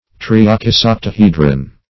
Search Result for " triakisoctahedron" : The Collaborative International Dictionary of English v.0.48: Triakisoctahedron \Tri"a*kis*oc`ta*he"dron\, n. [Gr.